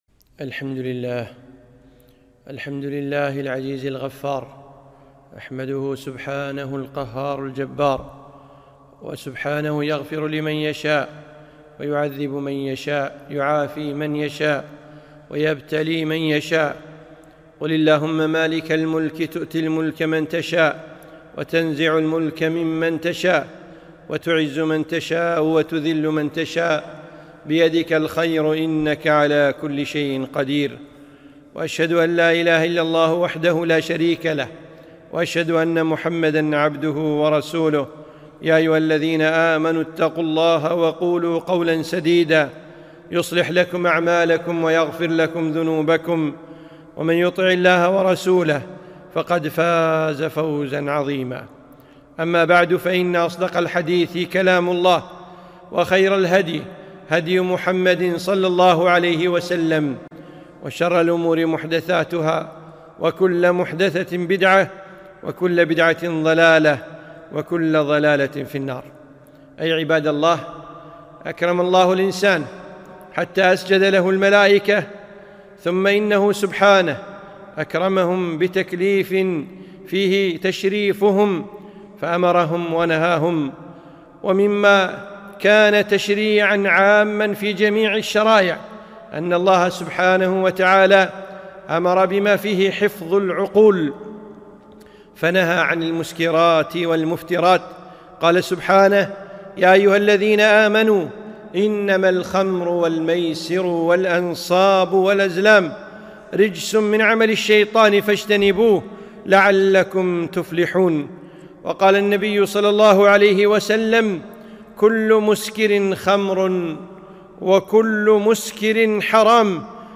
خطبة - حفظ العقول